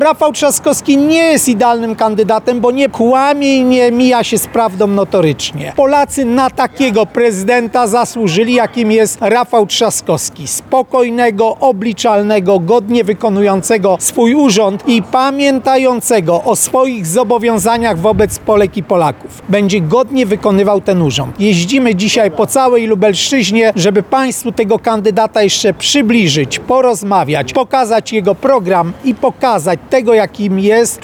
– Czasami, w trakcie tych rozmów, których za nami tysiące, słyszymy, że Rafał Trzaskowski też nie jest idealnym kandydatem – mówi senator Krzysztof Kwiatkowski.